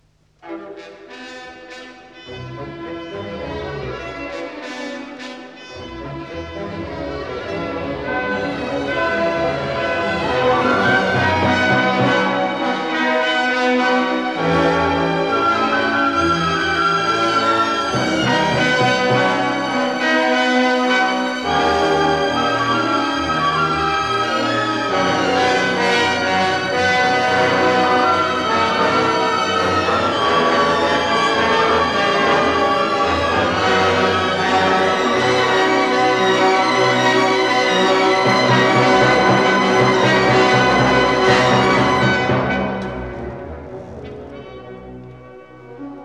Stereo recording made in July 1959 at the
Walthamstow Assembly Hall, London